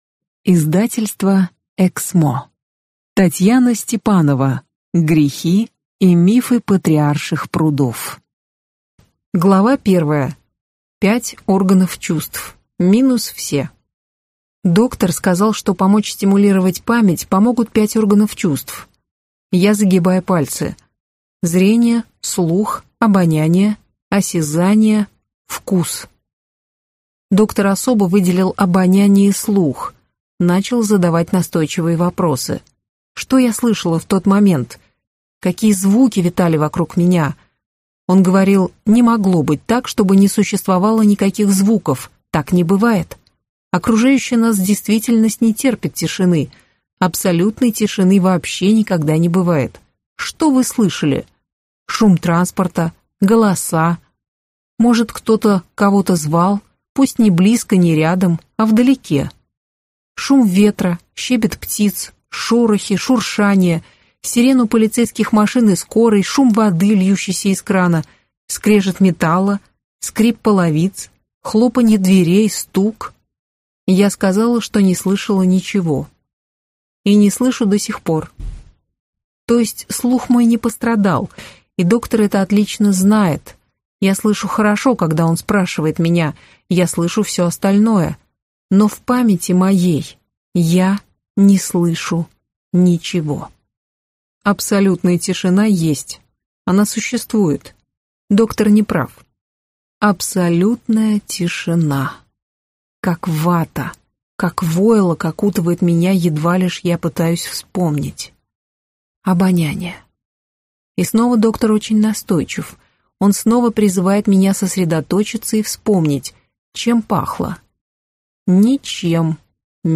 Аудиокнига Грехи и мифы Патриарших прудов | Библиотека аудиокниг